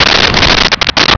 Sfx Whoosh 4802
sfx_whoosh_4802.wav